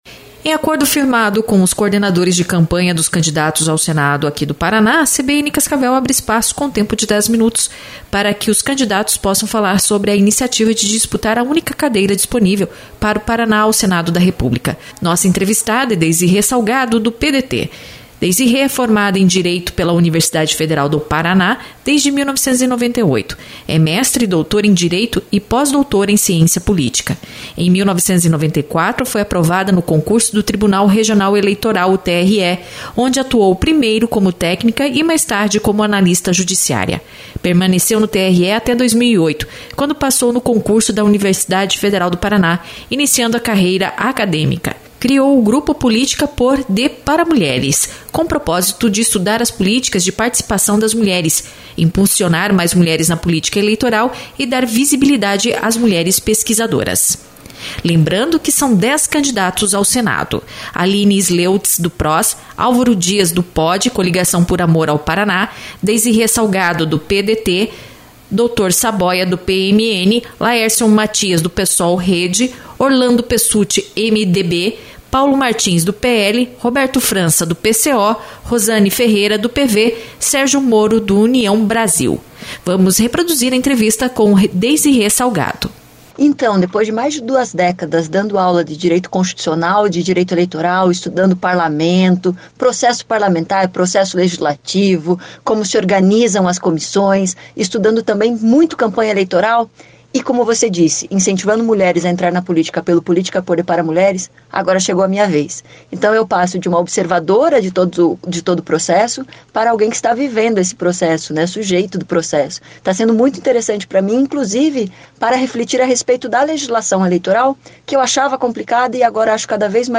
Editoriais
A CBN Cascavel abre espaço, com tempo de 10 minutos, para que os candidatos possam falar sobre a iniciativa de disputar a única cadeira disponível para o Paraná ao Senado da República.